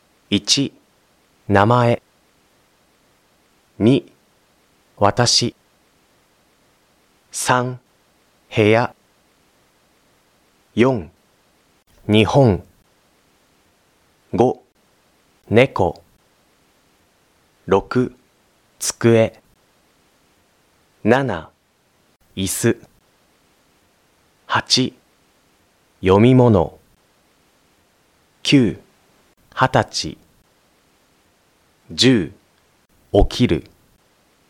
Module 1-1 Japanese Vowels, Consonants, and Basic Sounds